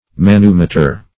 Search Result for " manumotor" : The Collaborative International Dictionary of English v.0.48: Manumotor \Man"u*mo`tor\, n. [L. manus the hand + E. motor.] A small wheel carriage, so constructed that a person sitting in it may move it.